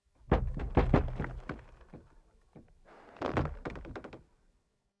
Sailboat Creaking
Wooden sailboat hull and rigging creaking rhythmically as it rocks on gentle waves
sailboat-creaking.mp3